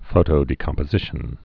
(fōtō-dē-kŏmpə-zĭshən)